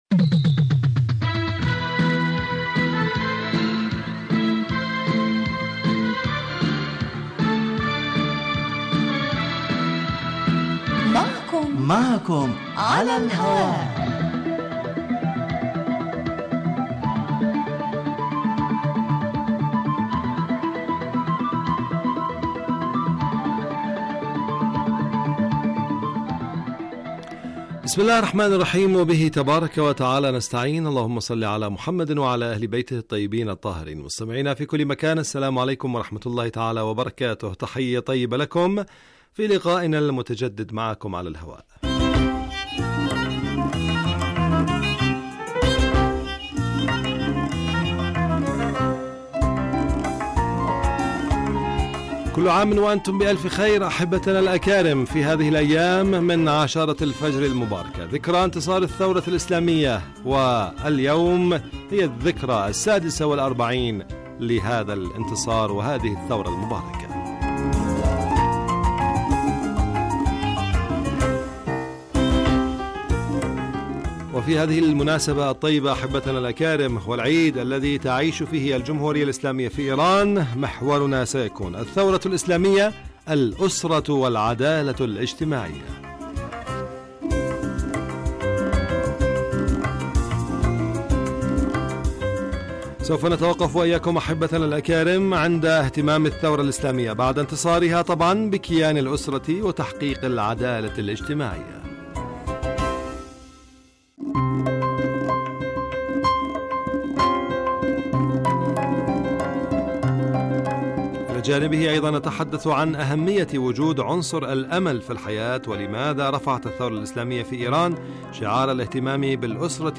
من البرامج المعنية بتحليل القضايا الاجتماعية في دنيا الإسلام و العرب و من أنجحها الذي يلحظ الكثير من سياسات القسم الاجتماعي بصورة مباشرة علي الهواء وعبر الاستفادة من رؤي الخبراء بشان مواضيع تخص هاجس المستمعين.
يبث هذا البرنامج مساء أيام السبت وعلى مدى خمسة وأربعين دقيقة.